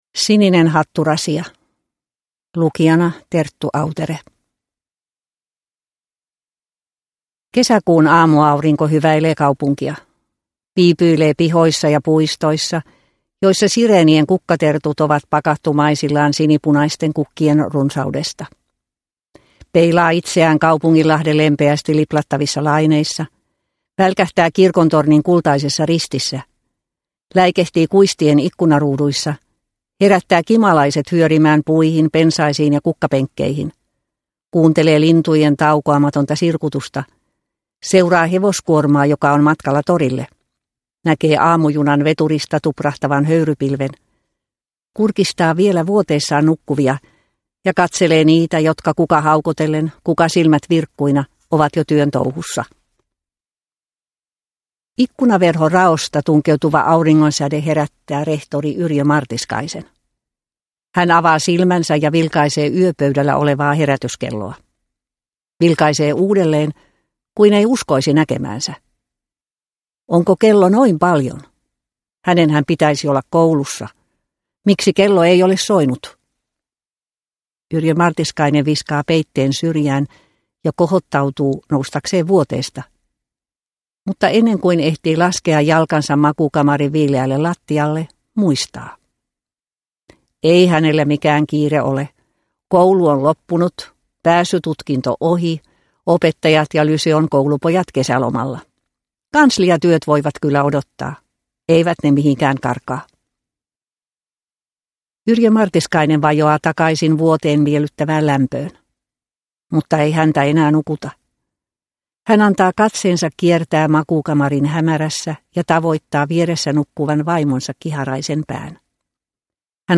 Sininen hatturasia – Ljudbok – Laddas ner